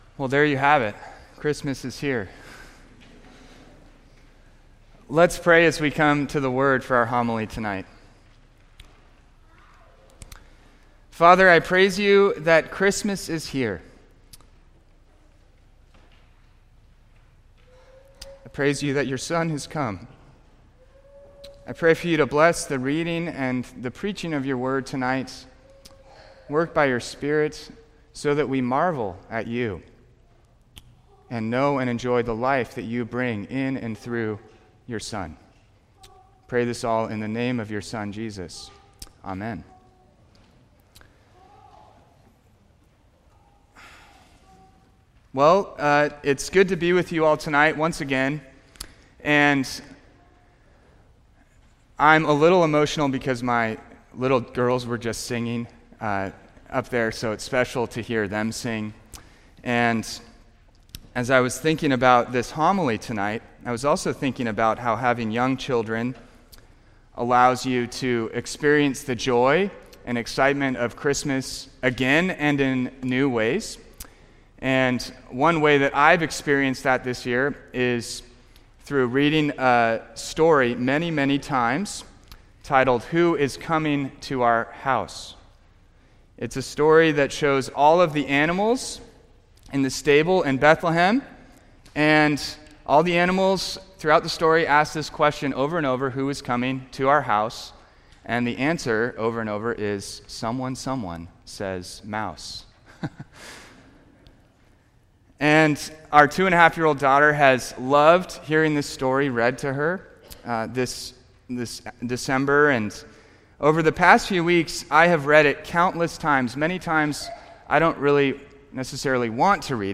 Christmas Eve Homily